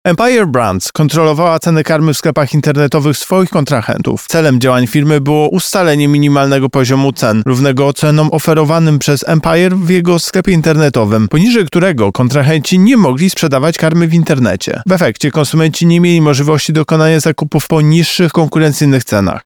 Nałożyłem loącznie prawie pół miliona złotych kar na Empire Brands oraz na dwie osoby zarządzające tą spółką – mówi Tomasz Chróstny, prezes Urzędu Ochrony Konkurencji i Konsumentów.
wypowiedz-prezesa-uokik-tomasza-chrostnego-2.mp3